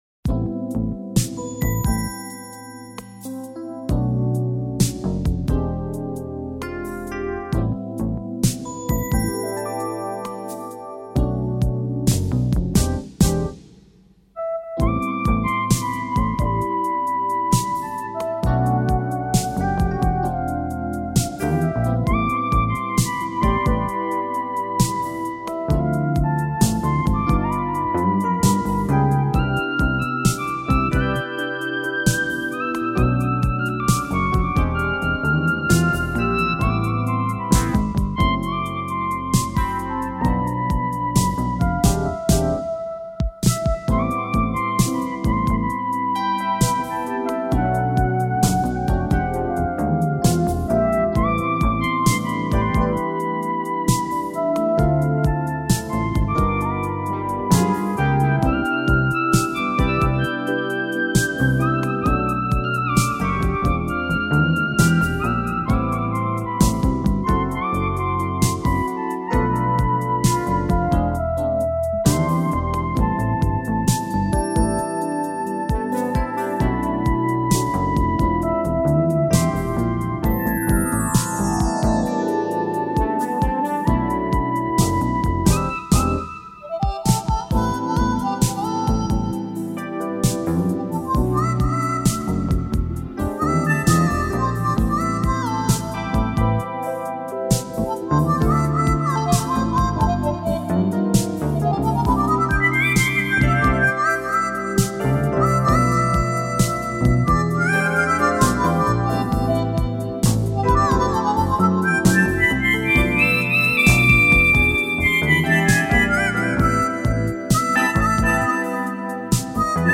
Очередные кассетные оцифровки.
ЧЕТВЕРТАЯ ЗАПИСЬ С РАДИО ЕВРОПА ПЛЮС НА ОПОЗНАНИЕ